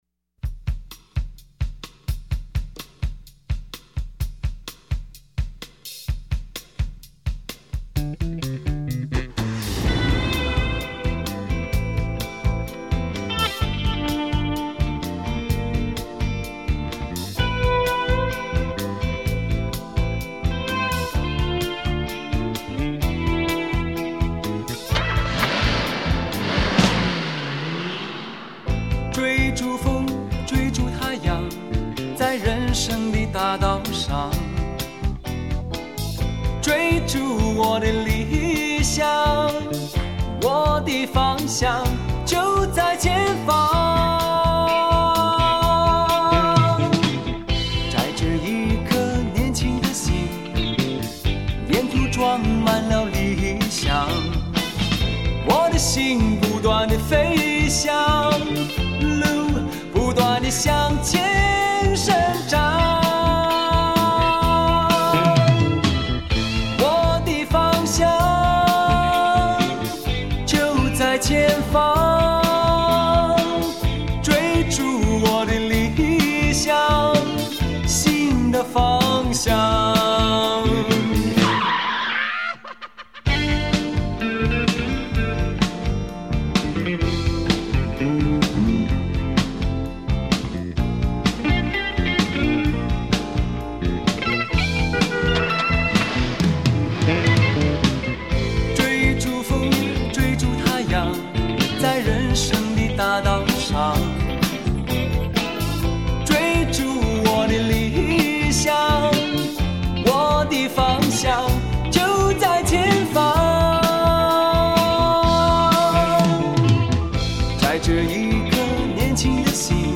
一个很会唱歌的男人，一个熟悉动听的声音。